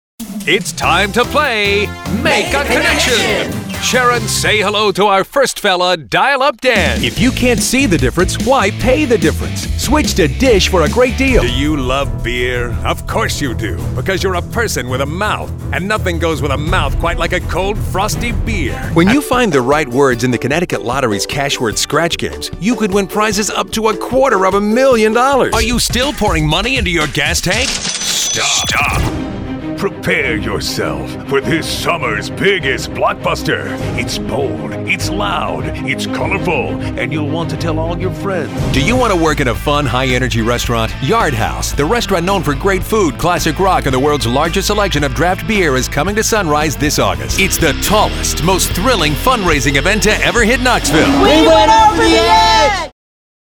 Mature Adult, Adult, Young Adult Has Own Studio
commercial